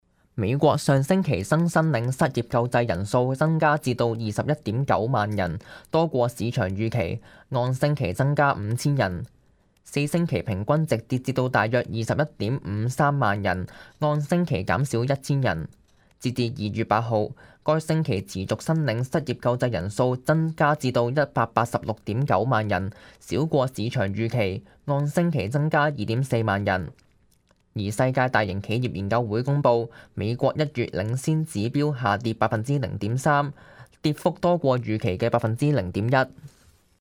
news_clip_22557.mp3